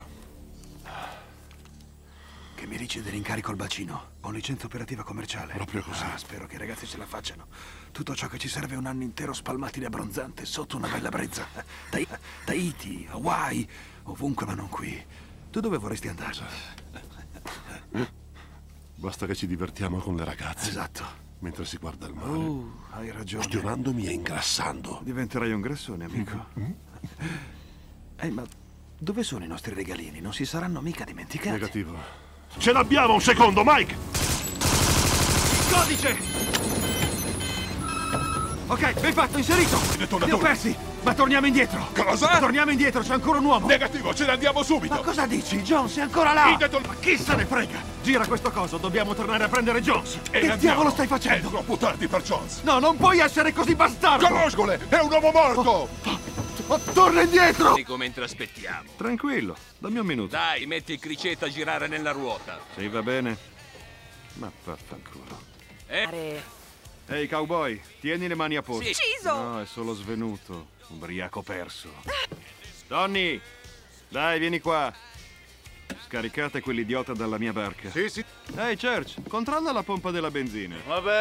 nel film "Oltre le linee nemiche", in cui doppia Thomas Ian Griffith.